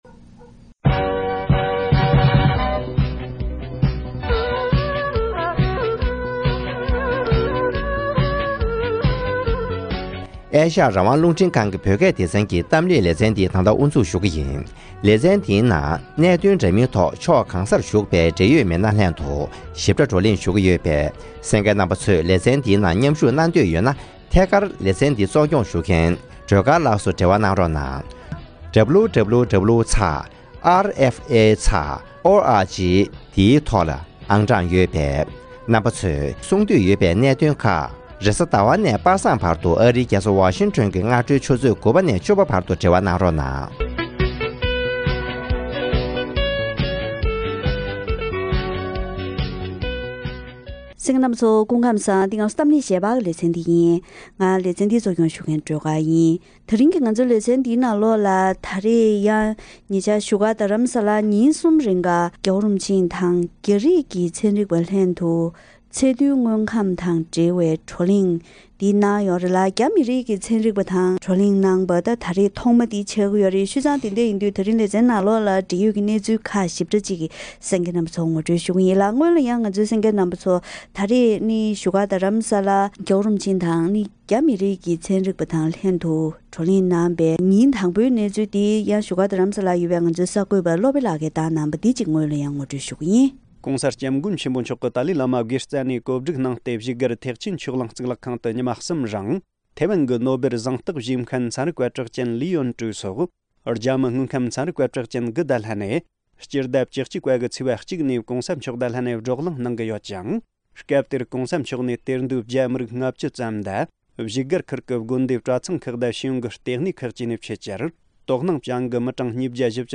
བགྲོ་གླེང་ཐོག་མཉམ་ཞུགས་གནང་མཁན་མི་སྣ་ཁག་ཅིག་དང་ལྷན་འབྲེལ་ཡོད་སྐོར་བཀའ་མོལ་ཞུས་པ་ཞིག་གསན་རོགས་གནང་།